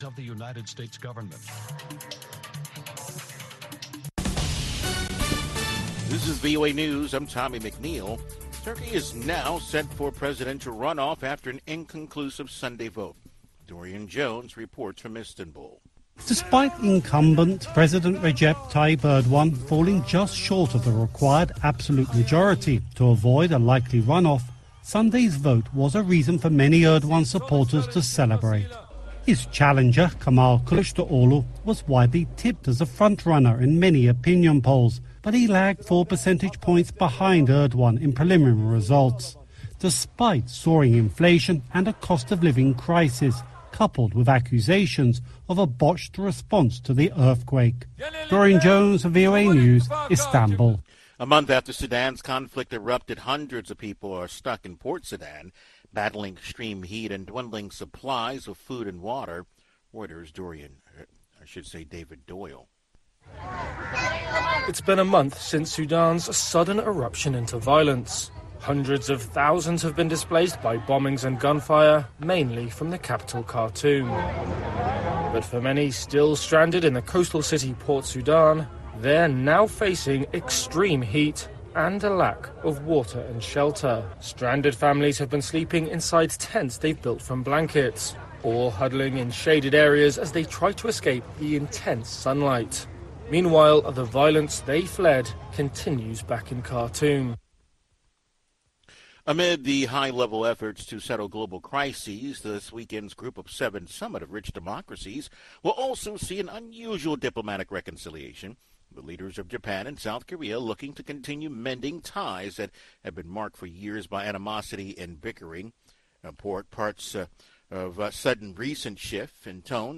News in Brief